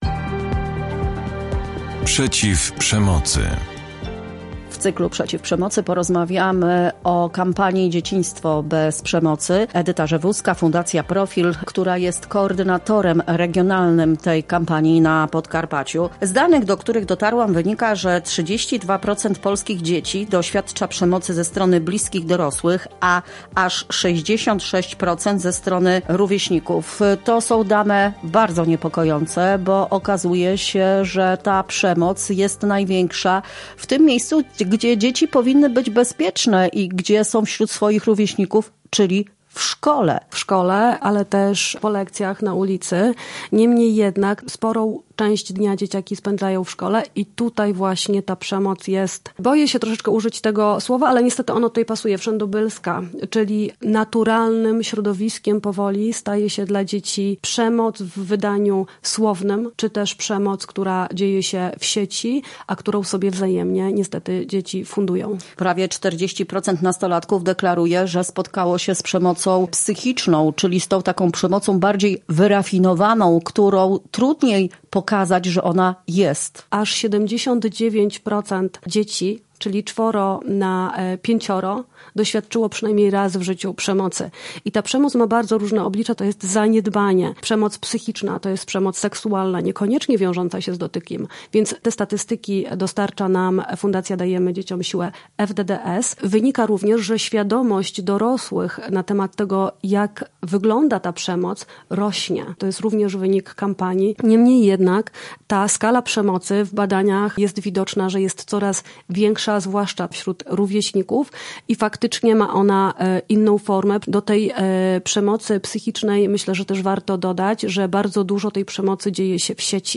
W cyklu „Przeciw Przemocy” rozmowa na temat kampanii